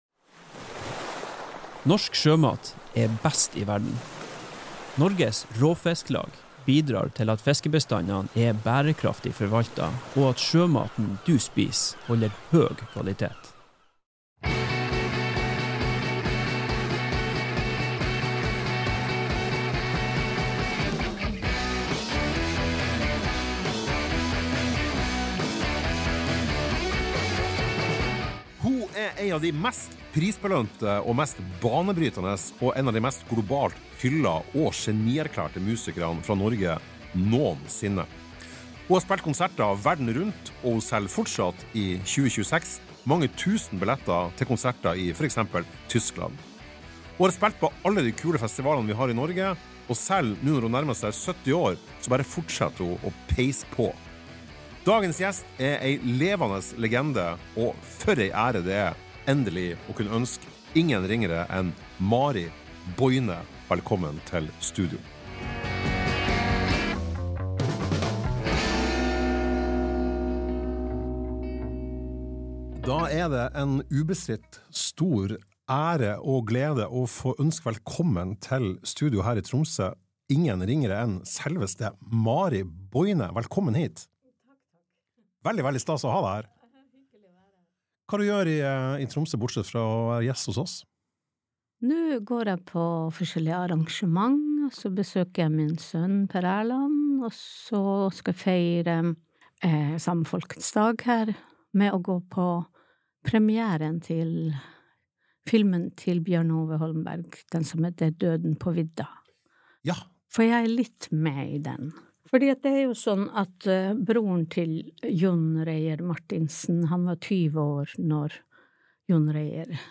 Hun har spilt på de fleste av de kule festivalene vi har og har hatt i Norge, og selv når hun nærmer seg 70 år, fortsetter hun å peise på med både konserter og plateutgivelser. Mari er en av de få vi med rette kan kalle en levende legende innen norsk musikkliv, og i studio med oss var hun i knallform, og attpåtil i en svært meddelsom modus.